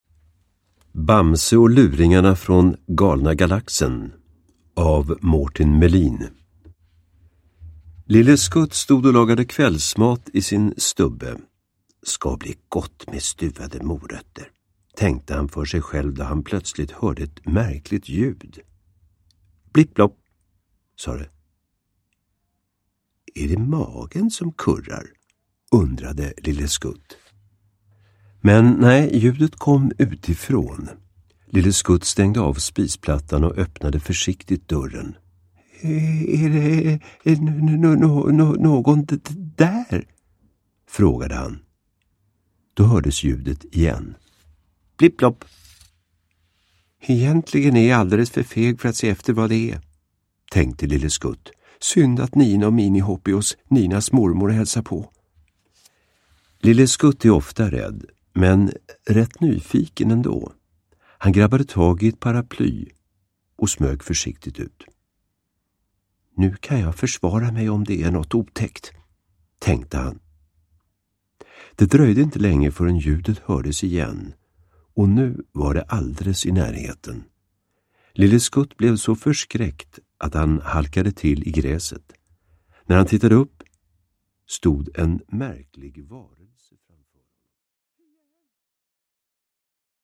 Bamse och luringarna från Galna Galaxen (ljudbok) av Mårten Melin